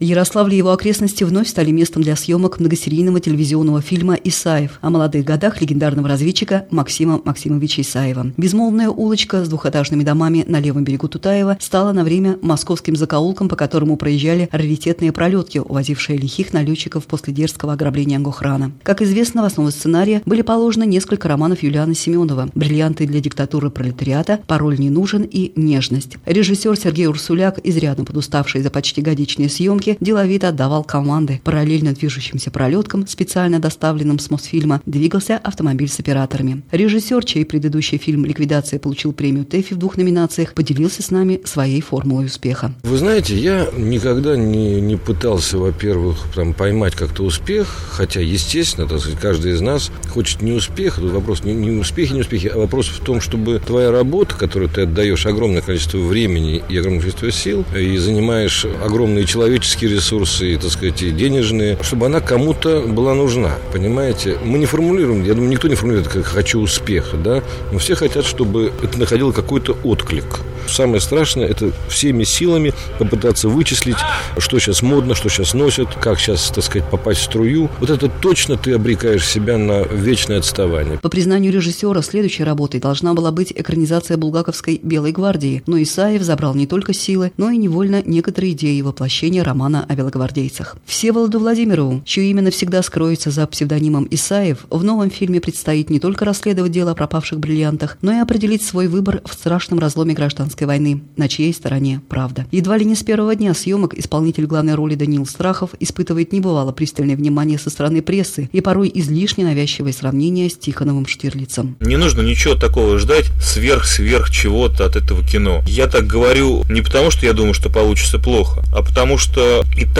Репортаж о съемках фильма "Исаев"
на радиостанции "Маяк-Ярославль"
Isaev_radio_Mayak.wav